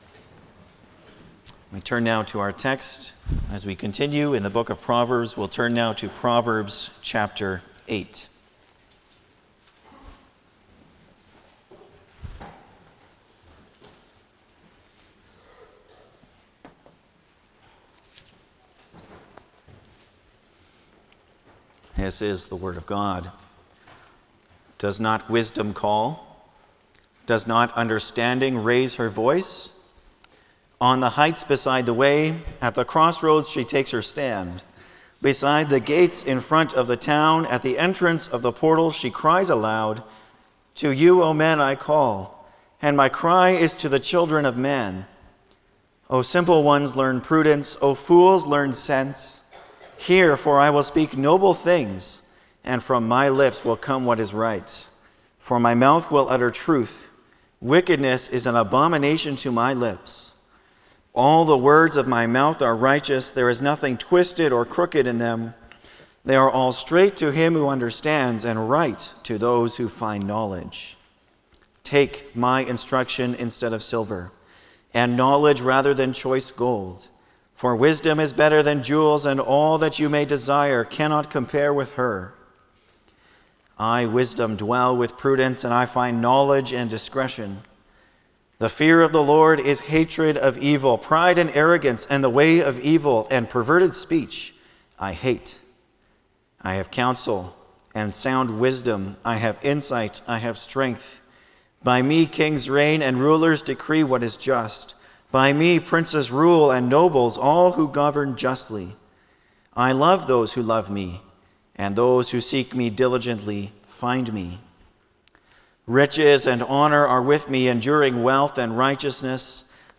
Service Type: Sunday morning
07-Sermon.mp3